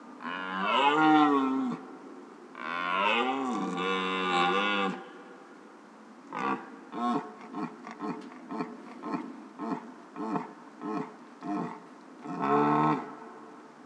Rentier Geräusche
Rentier-Geraeusche-Wildtiere-in-Europa.wav